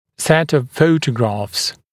[set əv ‘fəutəgrɑːfs][сэт ов ‘фоутэгра:фс]набор фотографий